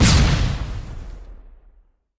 .开火7.ogg